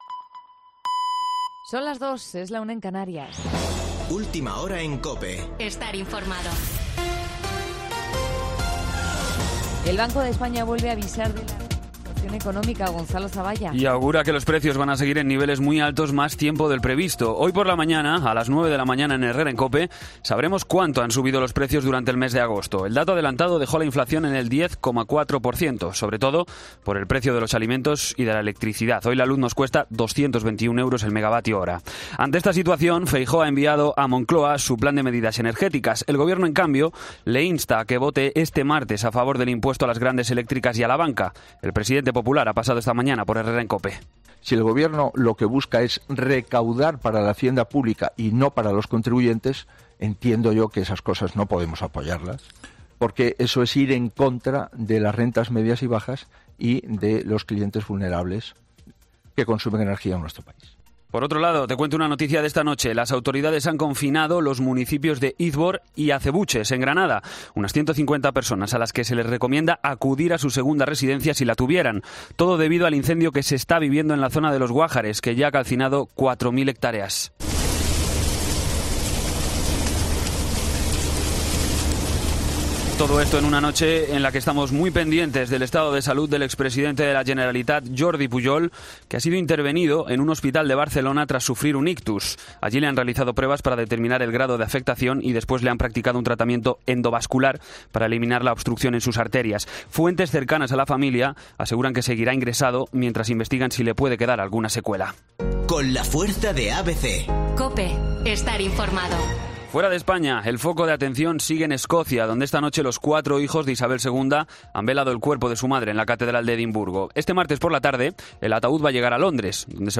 Boletín de noticias COPE del 13 de septiembre a las 02:00 horas